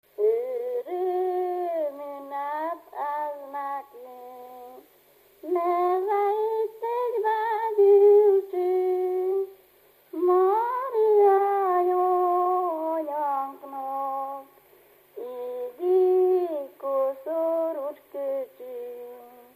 Dunántúl - Zala vm. - Zalavár
ének
Stílus: 9. Emelkedő nagyambitusú dallamok
Kadencia: 1 (5) 2 1